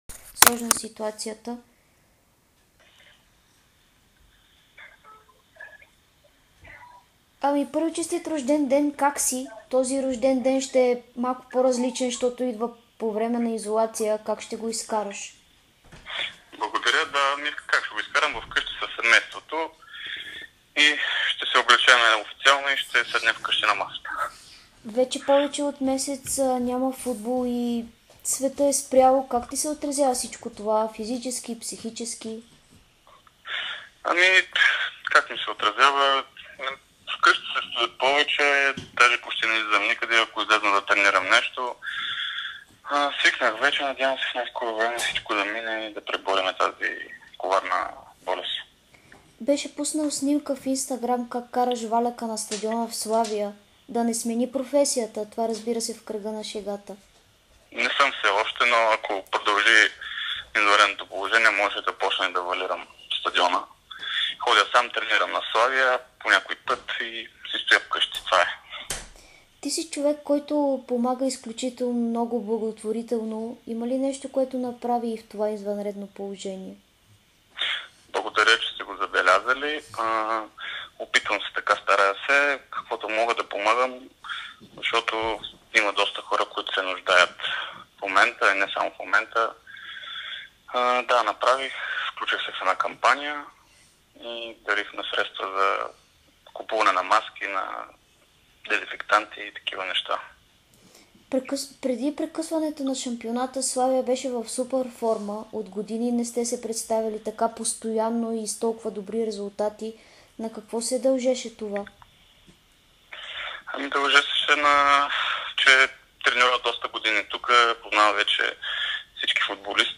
По време на карантината си стои у дома и спазва стриктно правилата. Той намери време да отговори на няколко въпроса за Дарик радио и dsport.